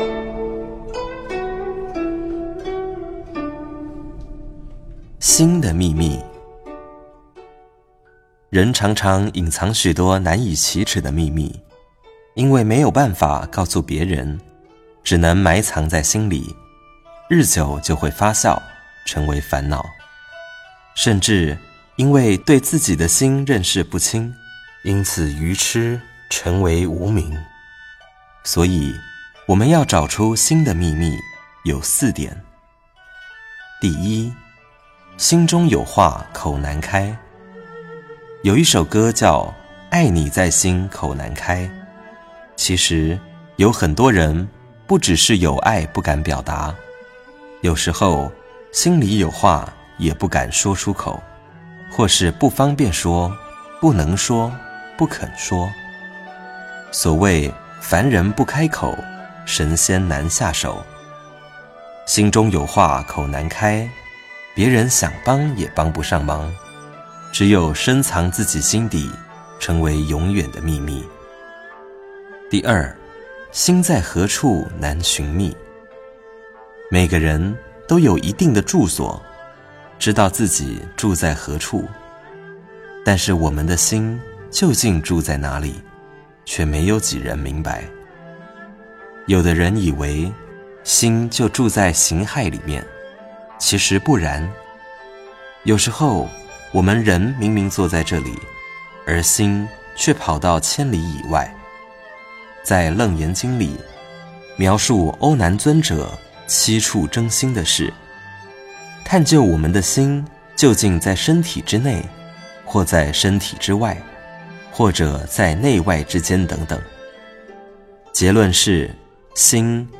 63.心的秘密--佚名 冥想 63.心的秘密--佚名 点我： 标签: 佛音 冥想 佛教音乐 返回列表 上一篇： 61.万事由心--佚名 下一篇： 65.心的妙用--佚名 相关文章 白衣观音大士灵感神咒--般禅梵唱妙音组 白衣观音大士灵感神咒--般禅梵唱妙音组...